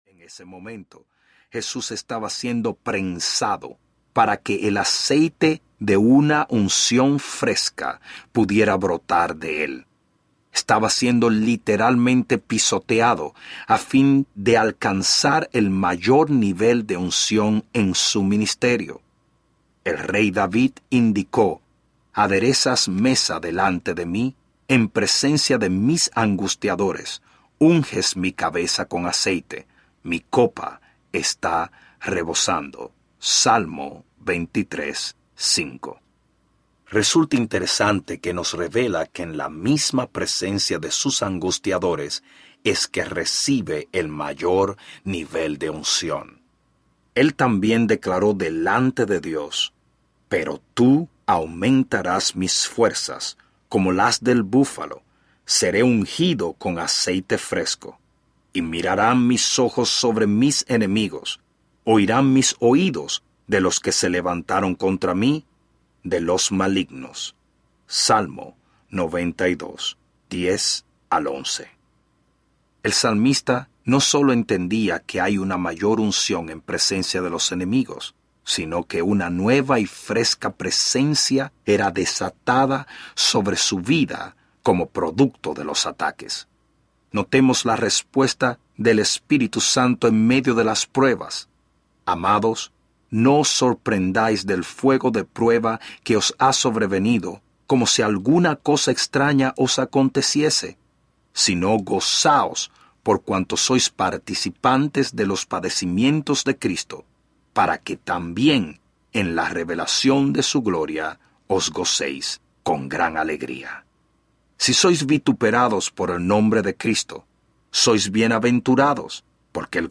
Vence La Adversidad Audiobook